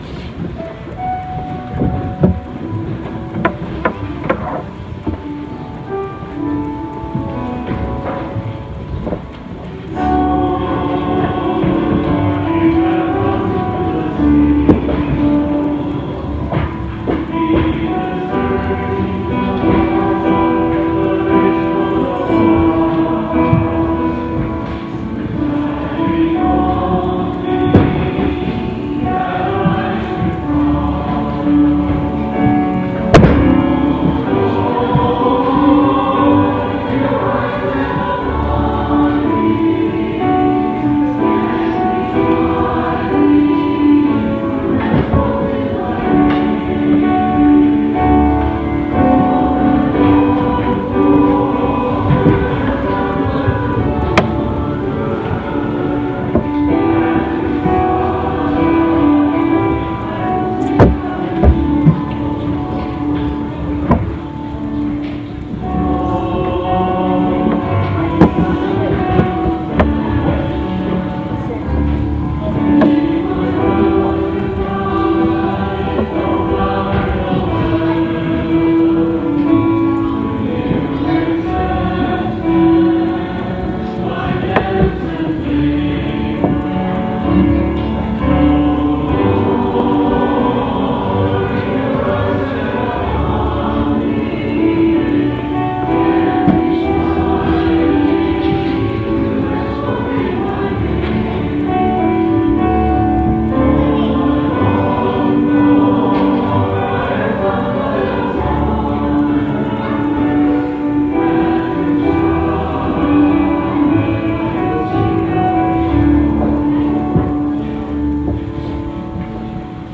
Lord, You Have Come - Choir with Piano - 2/10/2019